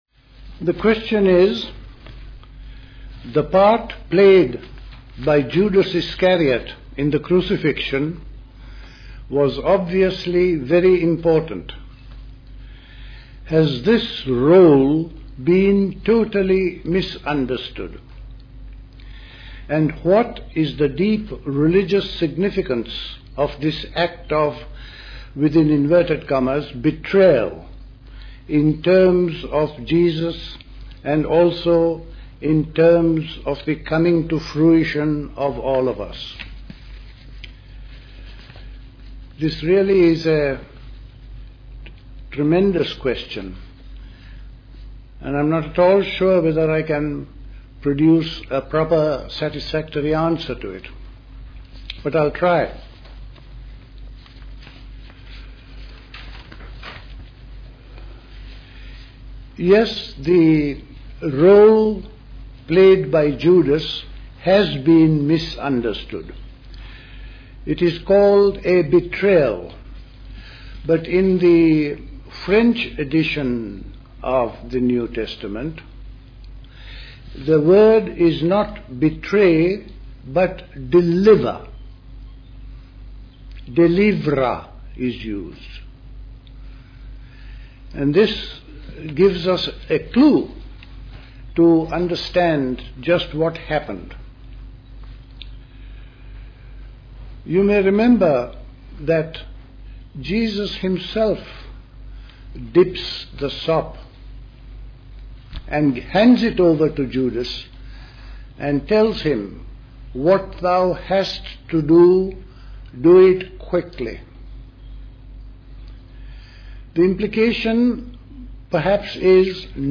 A talk
at the Convent of the Cenacle, Grayshott, Hampshire